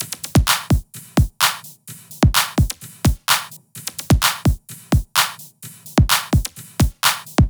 VFH1 128BPM Northwood Kit 3.wav